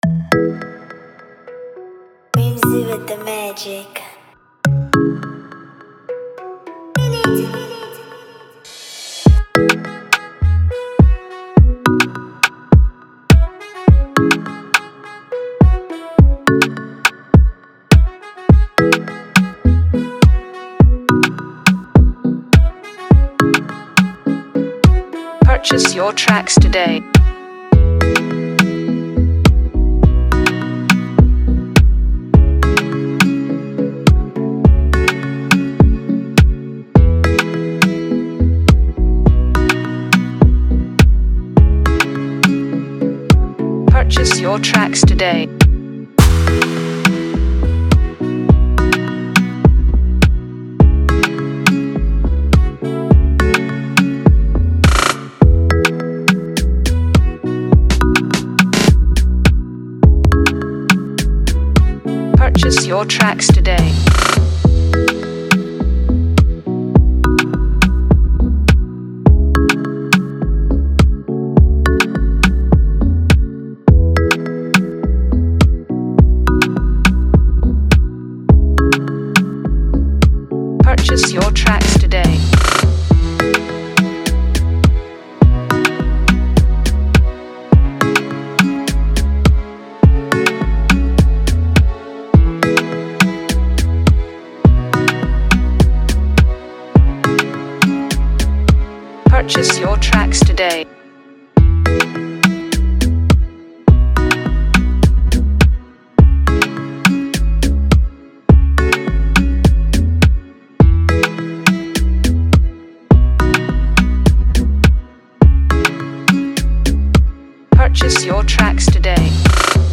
Download free Afrobeat instrumental
Download instrumental mp3 below…